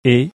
é in clé
e in bet